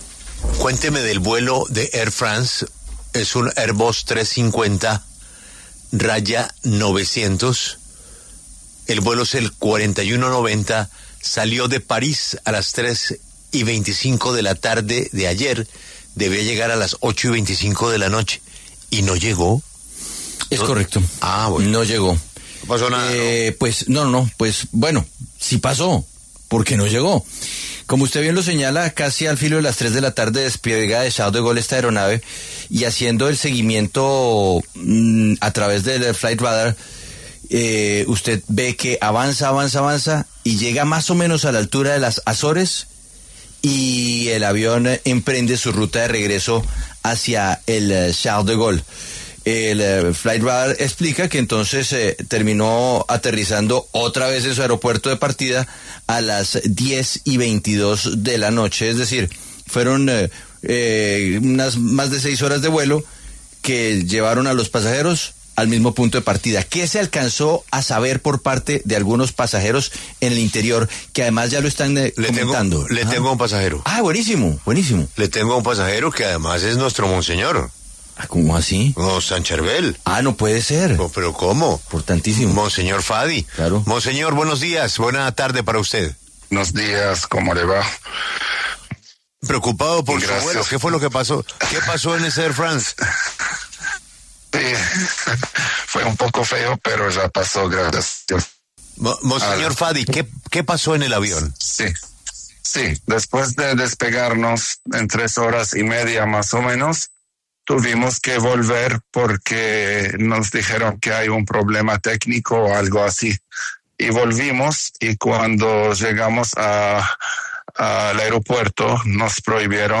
El monseñor Fadi Bou Chebl Abi Nassif, exarca apostólico para los fieles maronitas en Colombia, quien estaba a bordo del vuelo, relató cómo ocurrieron los hechos en La W.